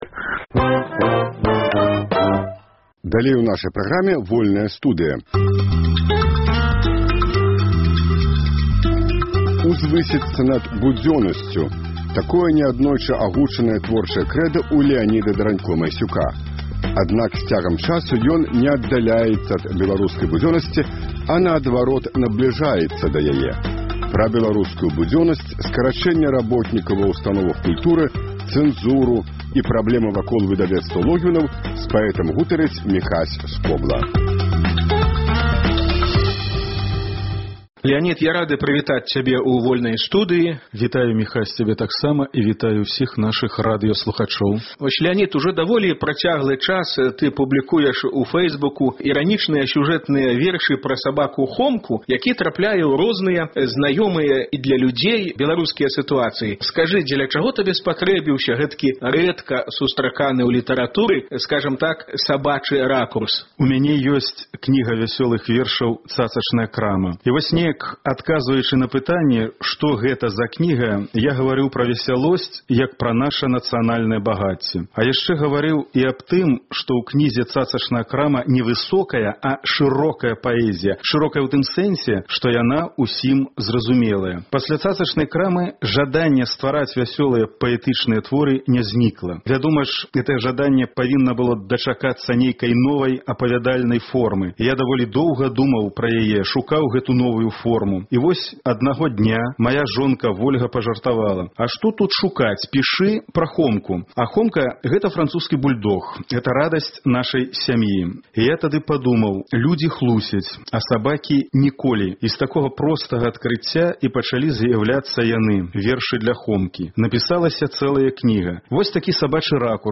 Пра беларускую будзённасьць — скарачэньне работнікаў ва ўстановах культуры, цэнзуру і праблемы вакол выдавецтва «Логвінаў» — мы гутарым з паэтам у «Вольнай студыі».